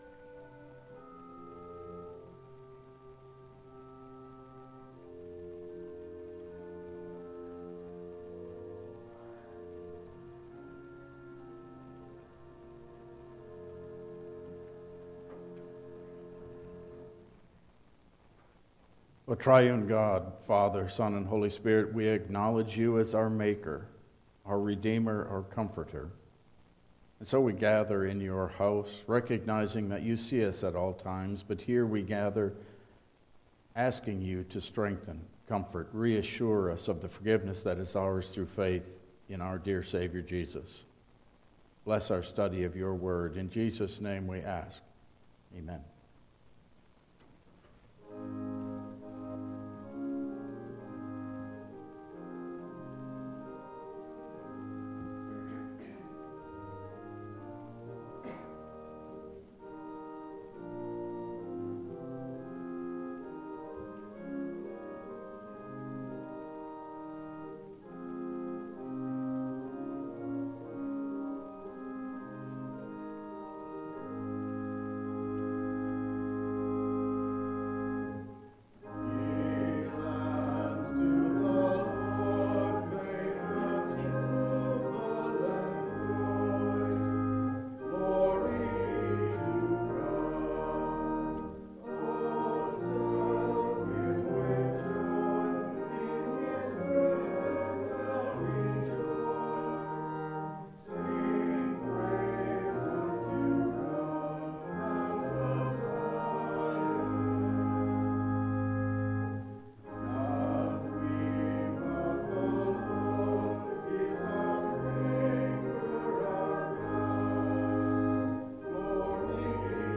Passage: Mark 2:23-3:6 Service Type: Regular Service